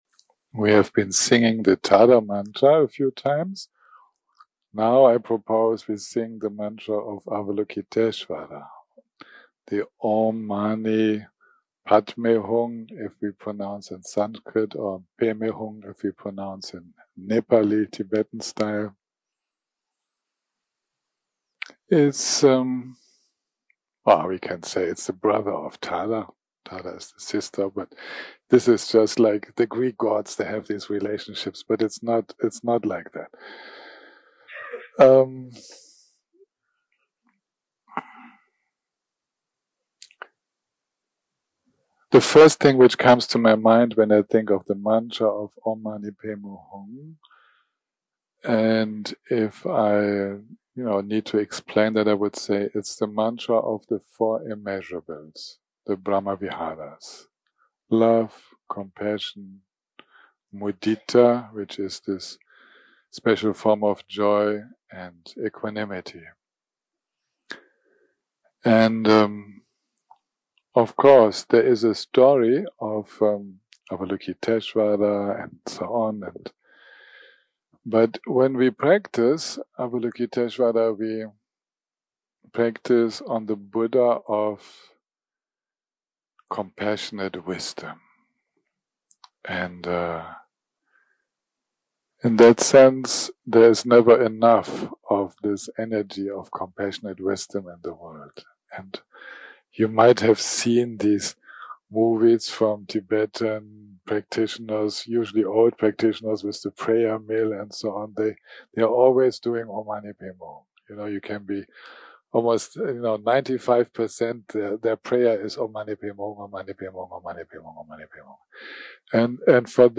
day 3 - recording 9 - Afternoon - Dharma Talk + Guided Meditation
day 3 - recording 9 - Afternoon - Dharma Talk + Guided Meditation 0:00 0:00 סוג ההקלטה: Dharma type: Dharma Talks שפת ההקלטה: Dharma talk language: English